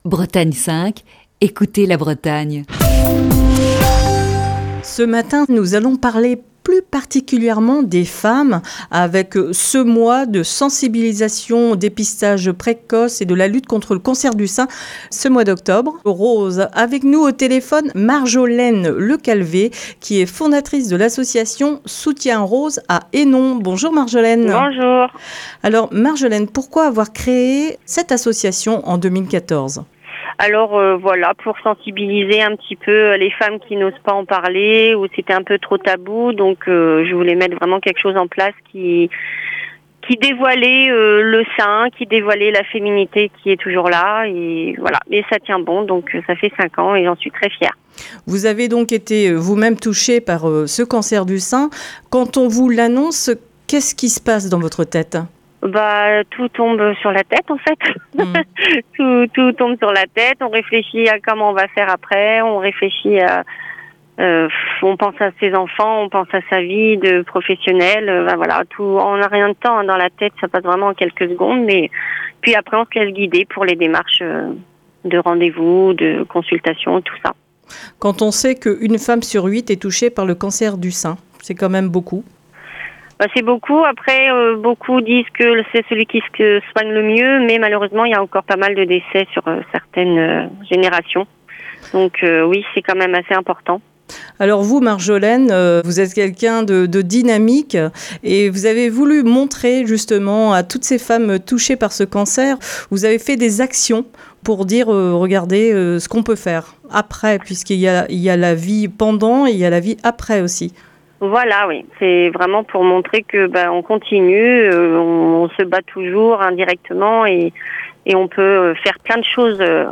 Émission du 7 octobre 2020.